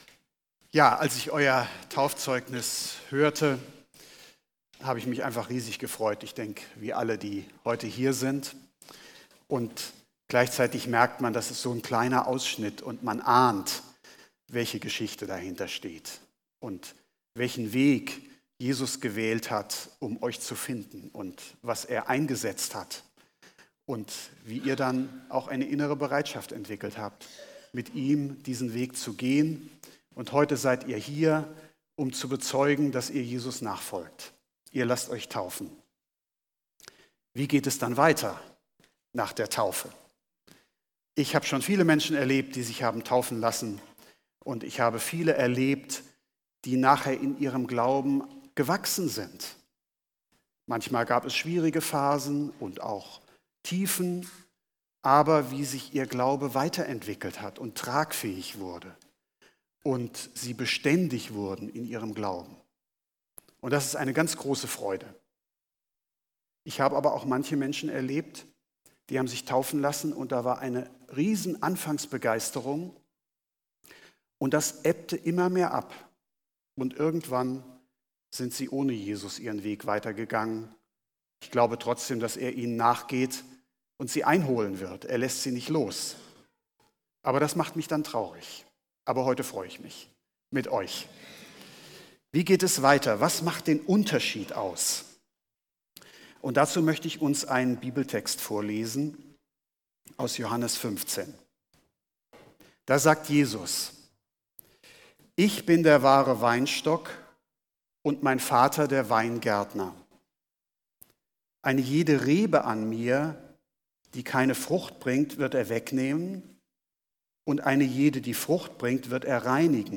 Gottesdienst